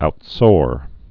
(out-sôr)